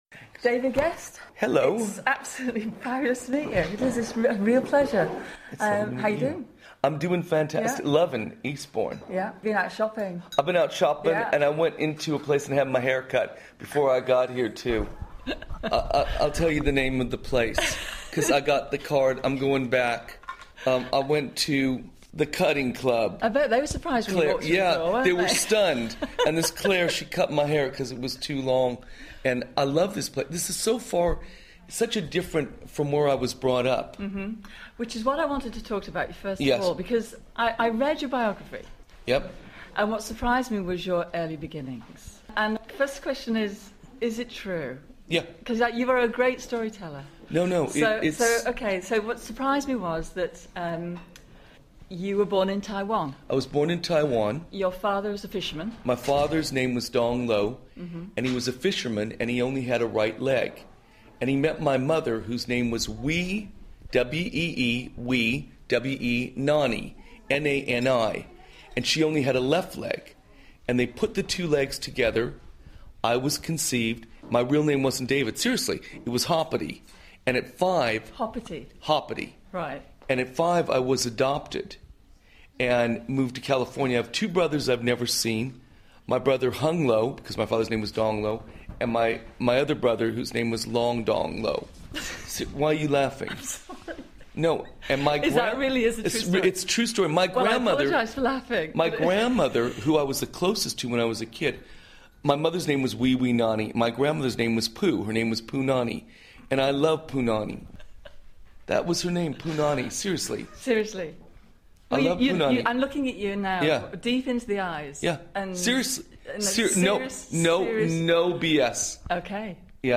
Interview with legendary producer and television personality David Gest
During the interview he reveals insights and outrageous stories of his many friends including his lifelong friend Michael Jackson, Whitney Houston, who was like a sister to him, and how Ray Charles came to his home and ate fried chicken! To mark the anniversary of his death, we played that interview on Drivetime.